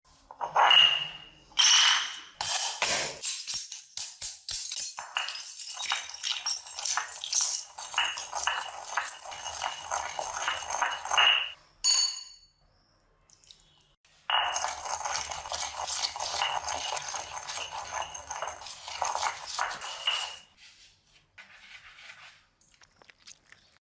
Upload By ASMR videos
Oddlysatisfying crushing light bulbs 💡😱